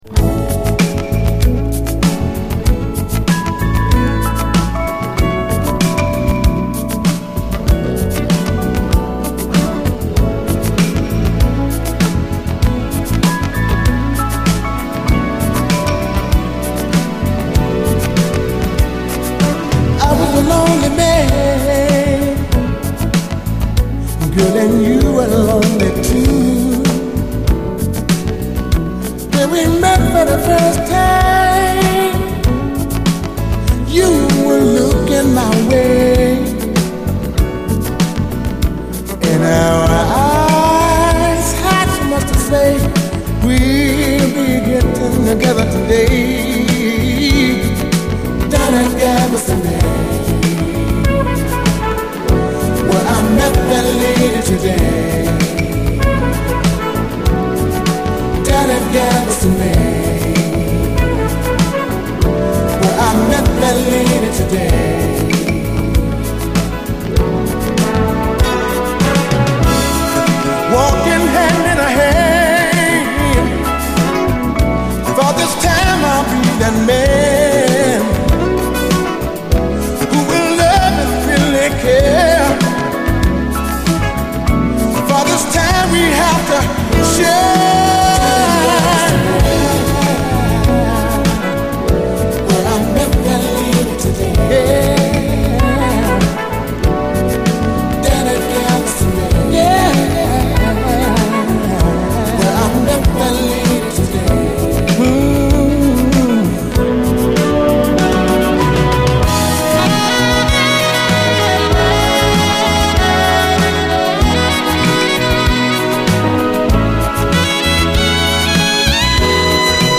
90年LA産インディー・モダン・ソウル
ビューティフル・シンセ・モダン・ソウル
ただただイイ曲としか言いようのない爽やかでエモーショナルな一曲！ガッツ溢れる熱いシンセ・モダン・ブギー・ダンサー